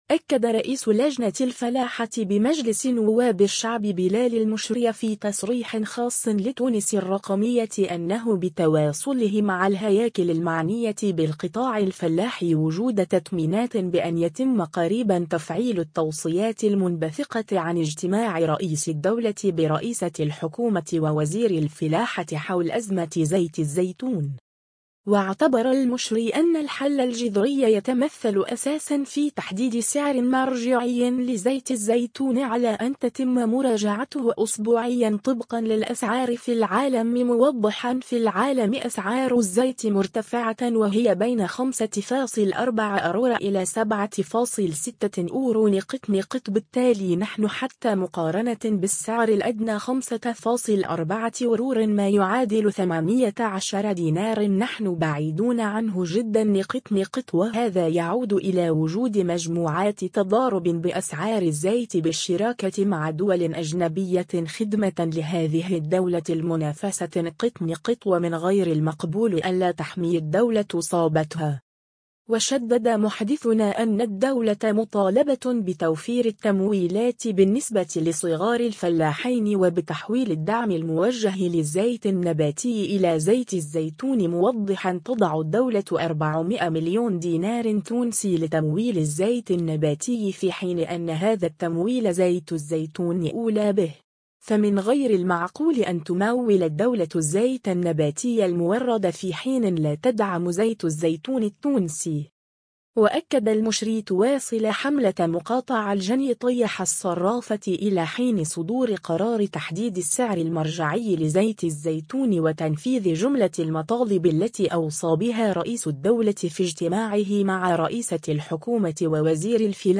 أكد رئيس لجنة الفلاحة بمجلس نواب الشعب بلال المشري في تصريح خاص لـ «تونس الرقمية” أنه بتواصله مع الهياكل المعنية بالقطاع الفلاحي وجود تطمينات بأن يتم قريبا تفعيل التوصيات المنبثقة عن اجتماع رئيس الدولة برئيسة الحكومة ووزير الفلاحة حول أزمة زيت الزيتون.